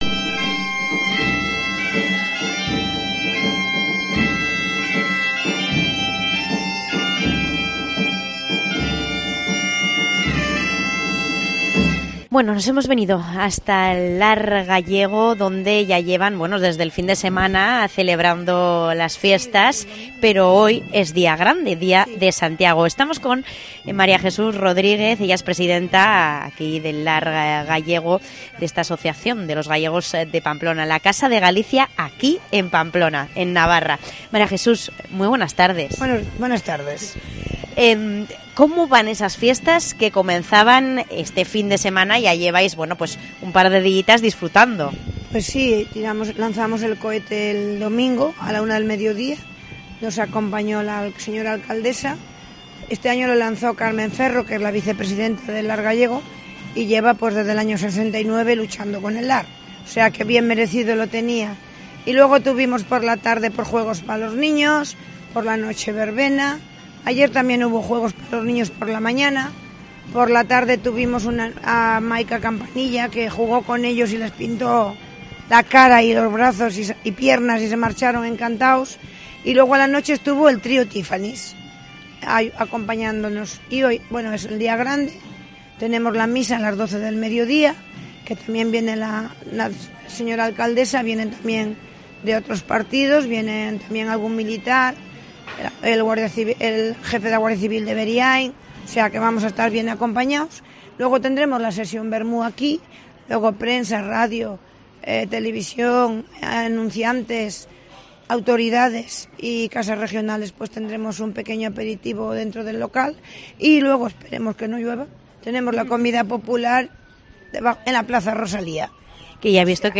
Hoy día de Santiago Apostol día grande para los gallegos nos hemos acercado hasta el Lar Gallego, la casa de los gallegos en Navarra